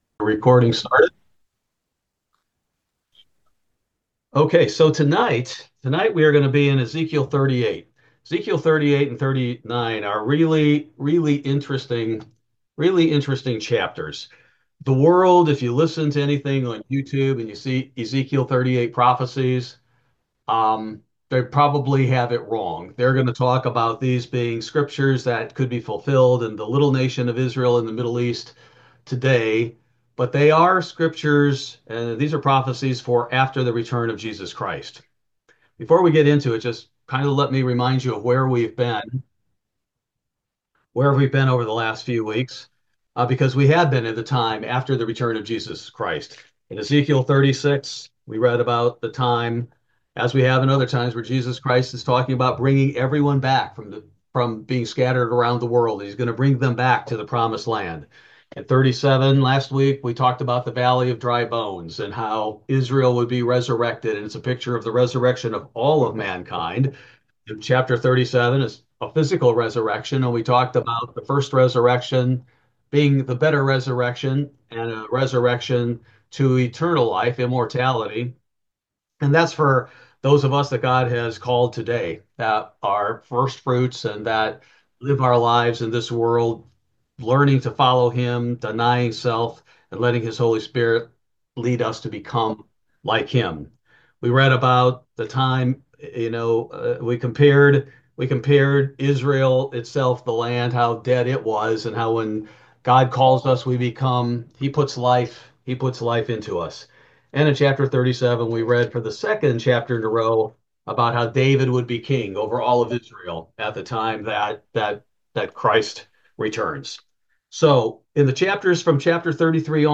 Ezekiel Bible Study: March 12, 2025